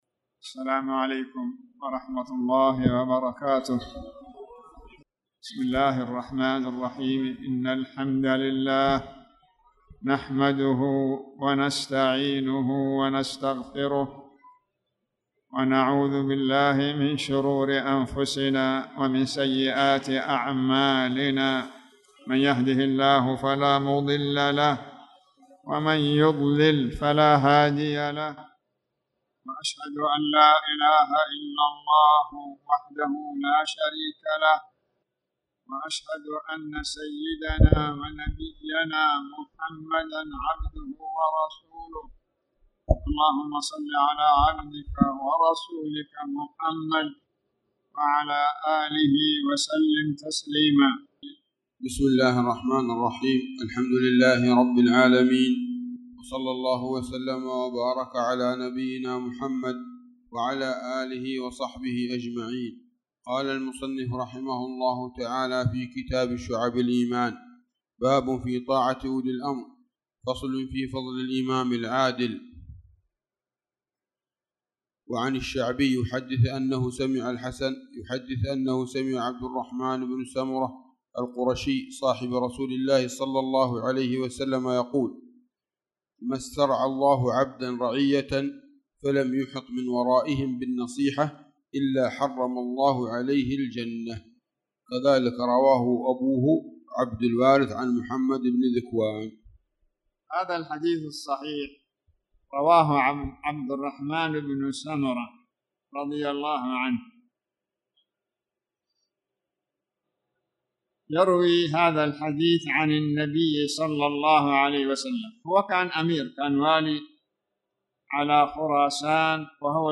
تاريخ النشر ١ شعبان ١٤٣٧ هـ المكان: المسجد الحرام الشيخ